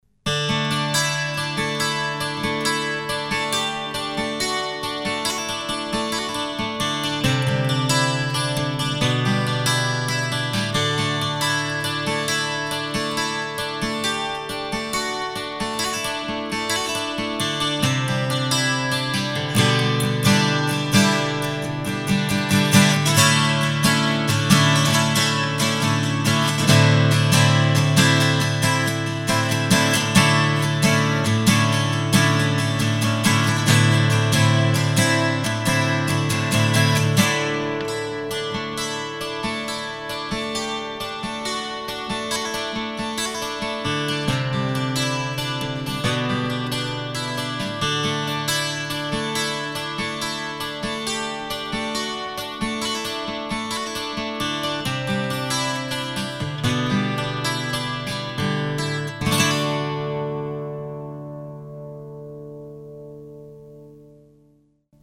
K.Yairi DY28-12 の音 ― 2007年12月16日
下手な演奏ですが録音してみました。何れもミキサーからProteusX+CubaseLEにてＰＣで録音しています。同梱のソフトエフェクト（リバーヴ）をう～すくかけています。
（mp3：約2.6MB） 弦交換してから２週間位経ってからの録音です。結構下の方の音も出ていると思いますが・・・。